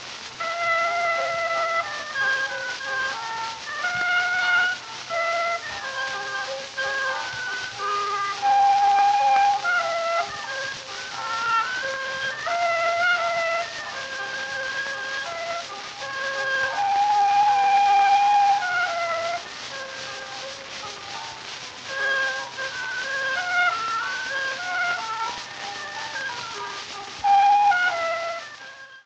Hier drei Hörbeispiele zum akustischen Verdeutlichen der genannten Zahlenverhältnisse:
Freischütz-Fantasie, um 1902. Direktaufnahme im Standardformat, Ausschnitt.